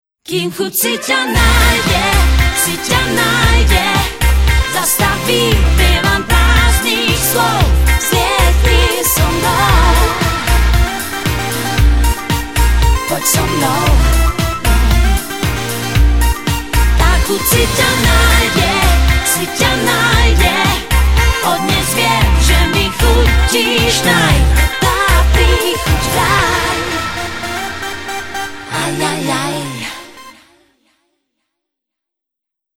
REKLAMU vo formáte mp3.